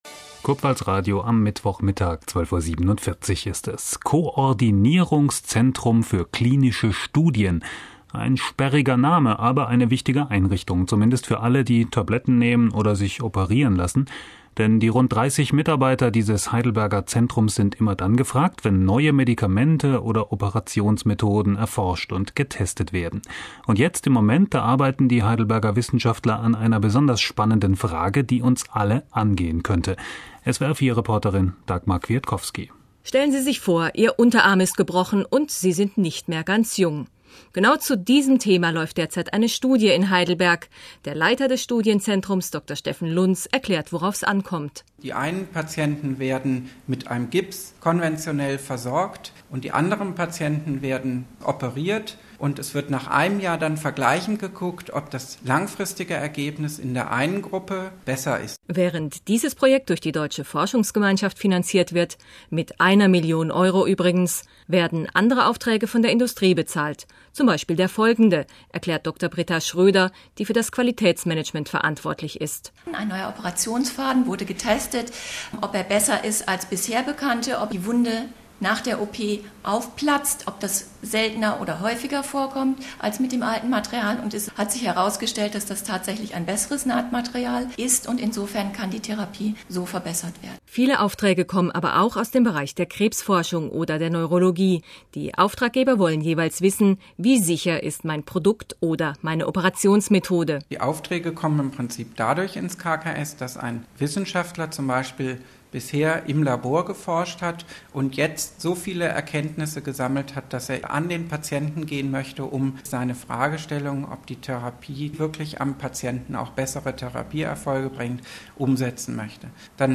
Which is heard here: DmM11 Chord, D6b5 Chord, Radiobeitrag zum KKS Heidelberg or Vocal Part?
Radiobeitrag zum KKS Heidelberg